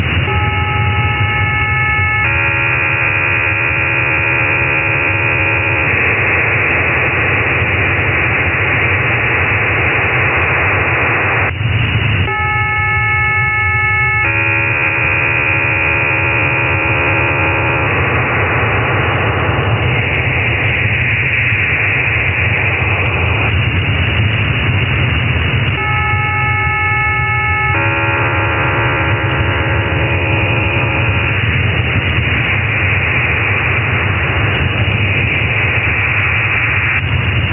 The Israeli Navy hybrid modem is composed of three parts:
• A 2‑second long 6 tone preamble
• a part of parallel data transmission in QPSK
• a final part with serial data according to the MIL 188 – 110 standard: 1800 Hz carrier modulated in PSK8 at 2400 bauds.